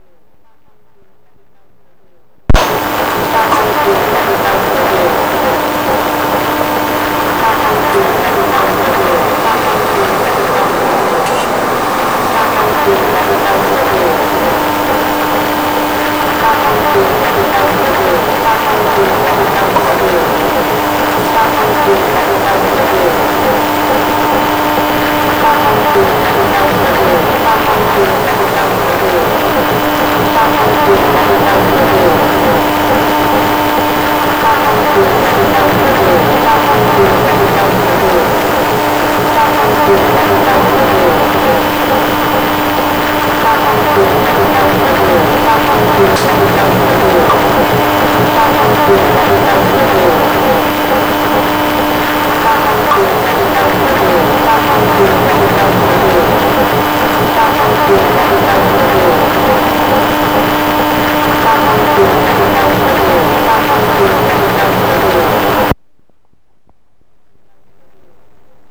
One such example is below using a FM transmitter:
isochronic signal 15 Khz recording of mixed single channel
isochronic signal 15 Khz with embedded message air type recording
isochronic-signal-15-Khz-with-embedded-message-air-type-recording.mp3